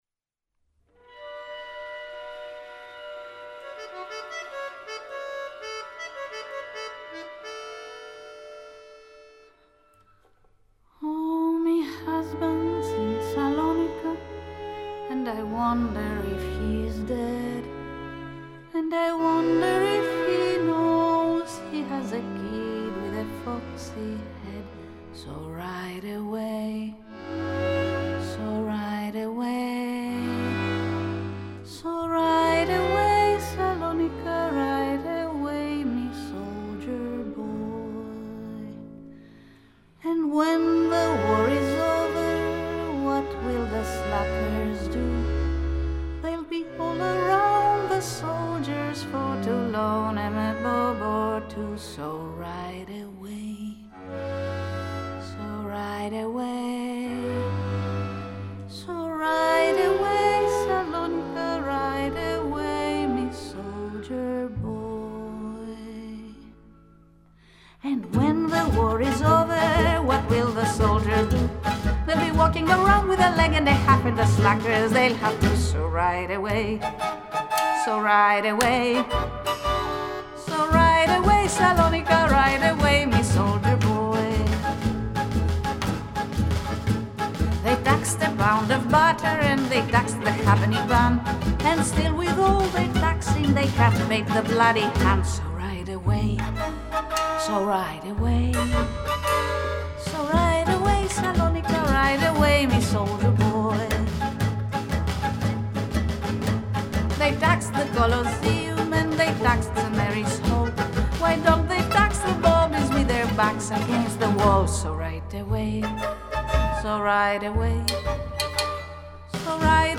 Жанр: Alternatif.